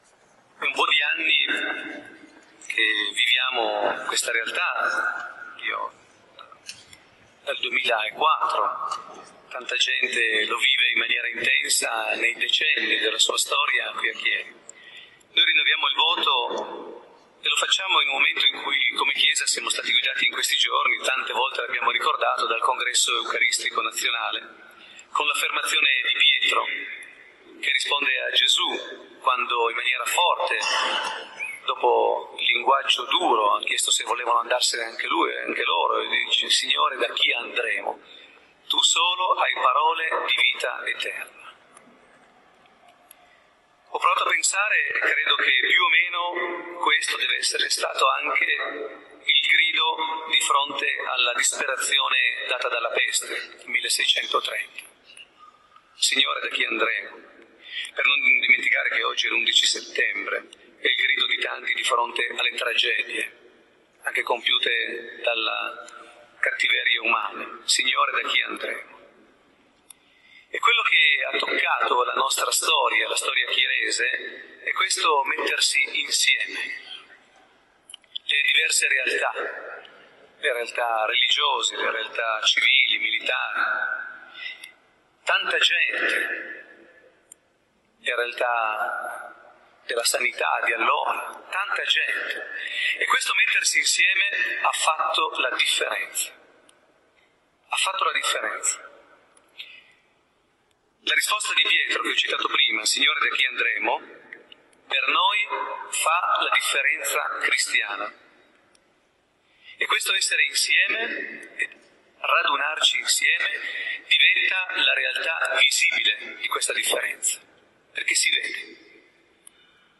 Omelia
durante la funzione votiva della Salve, in occasione dei festeggiamenti della Madonna delle Grazie 2011.